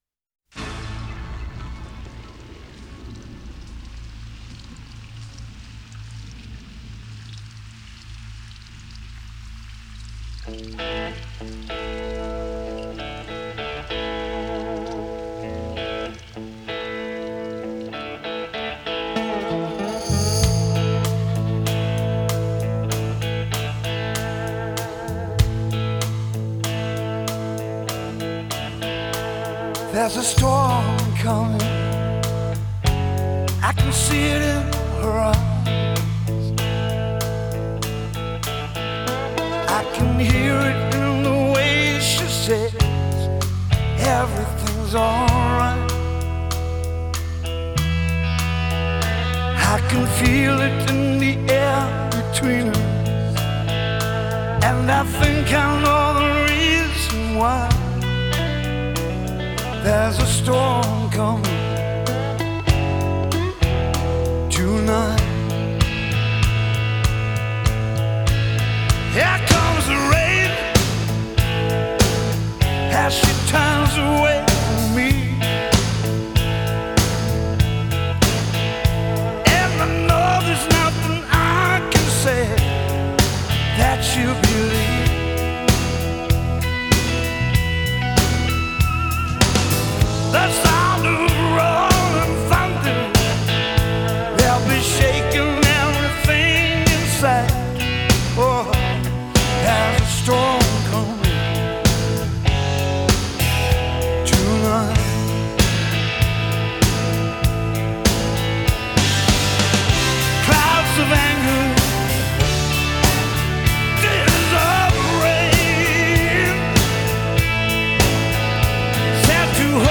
характерным хриплым вокалом